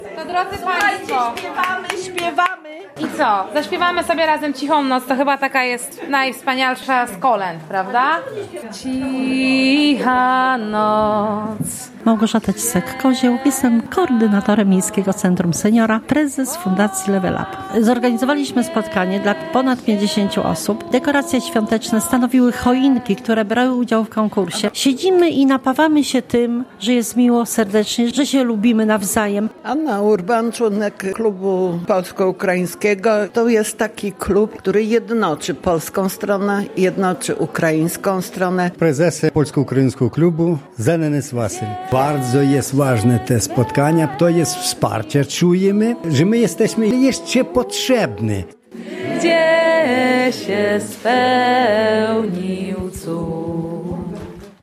Wigilia w Miejskim Centrum Seniora
Był wigilijny poczęstunek, wspólne kolędowanie i rozmowy.